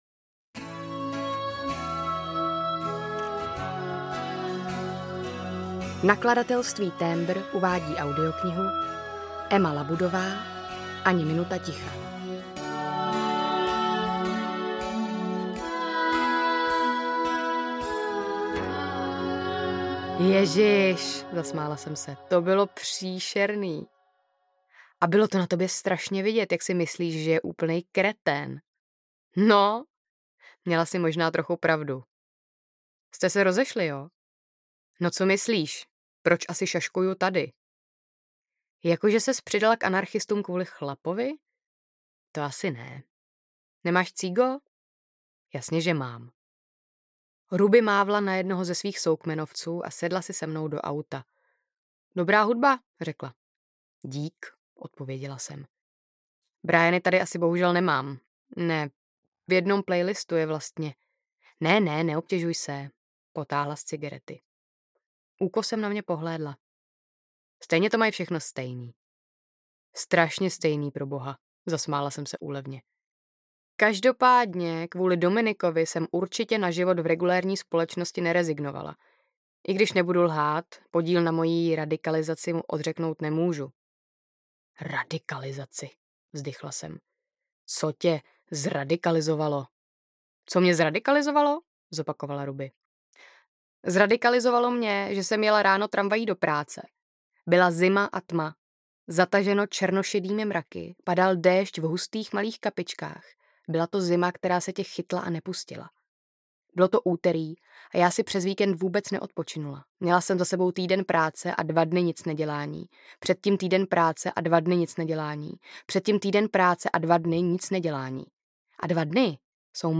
Ani minuta ticha audiokniha
Ukázka z knihy